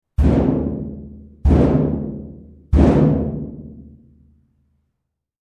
Door Knock ringtone free download
Sound Effects